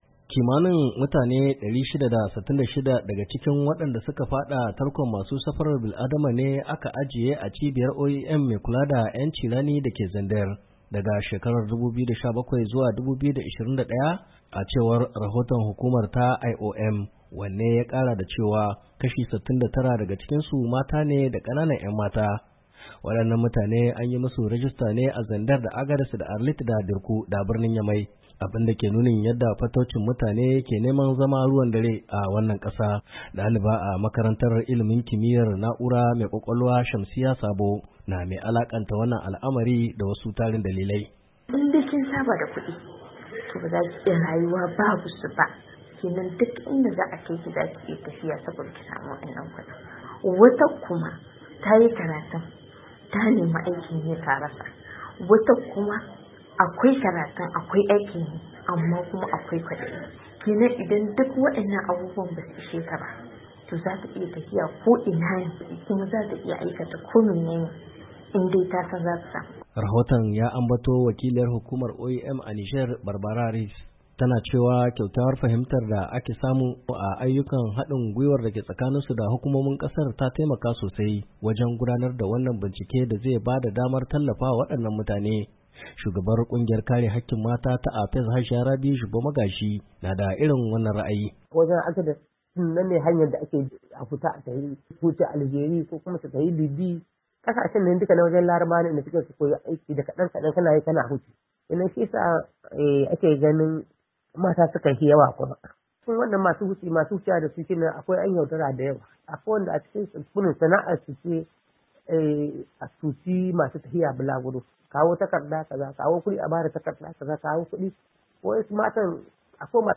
wannan rahoto daga birnin yamai